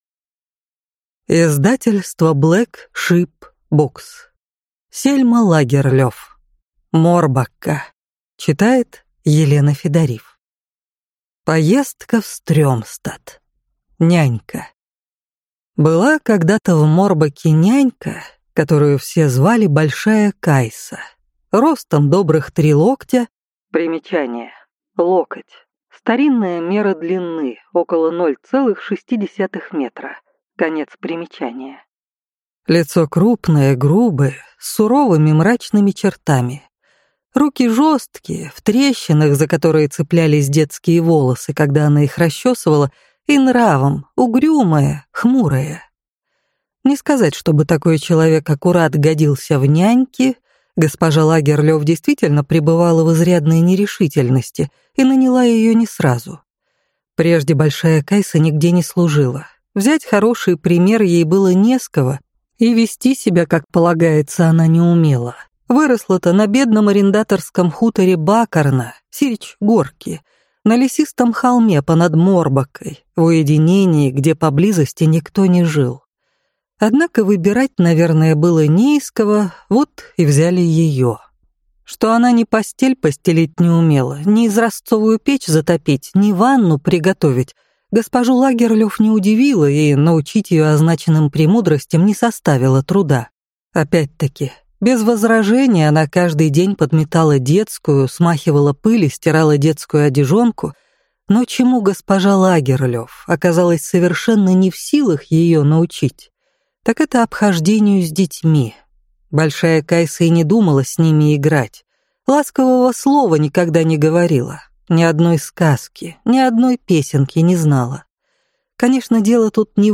Аудиокнига Морбакка | Библиотека аудиокниг